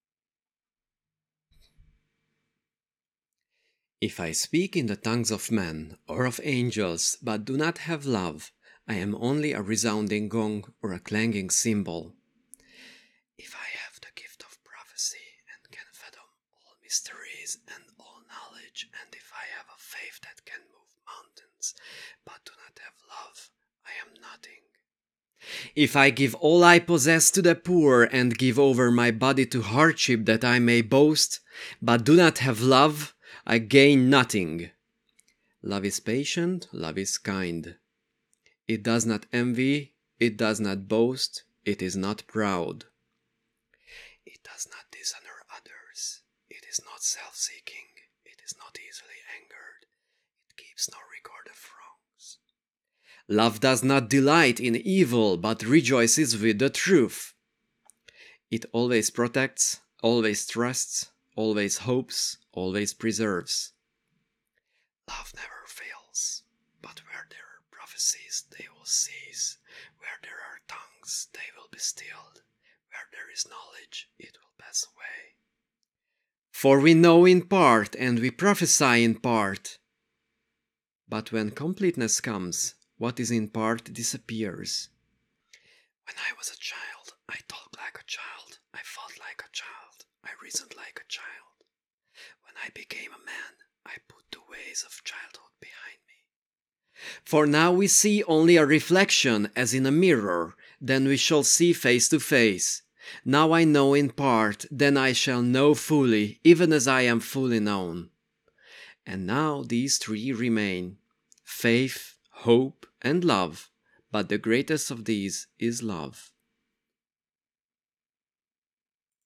This helps us remove the noise between words and sentences by cutting it to silence.
Equalization
This command gradually attenuates frequencies below 100hz, as there are not much valuable content in there, but it can really lower the clarity of the speech.
This is mostly needed because we have a lot of high-frequency noise, so this is a workaround for those.